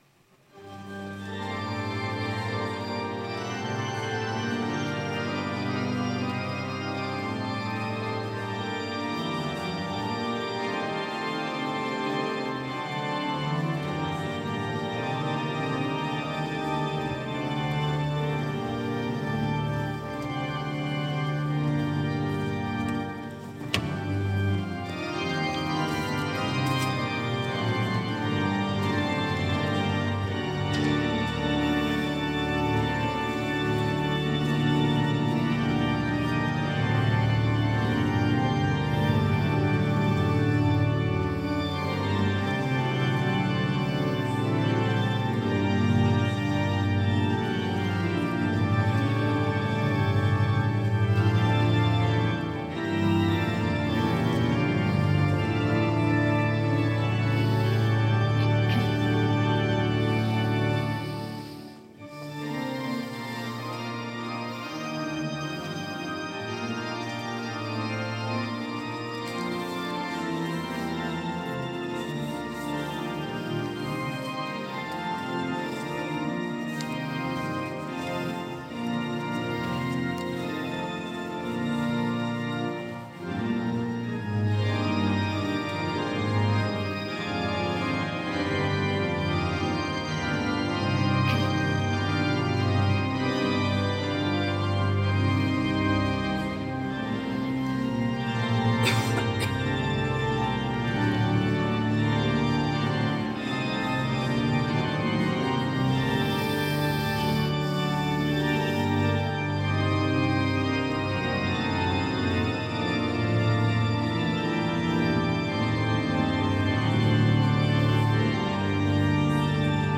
Écouter le culte entier (Télécharger au format MP3)
Vidéo de la partie centrale du culte Afin de visualiser les vidéos il est nécessaire d'accepter les cookies de type analytics Culte à l’Oratoire du Louvre Dimanche 25 octobre 2020 Tout blasphème sera pardonné.
Orgue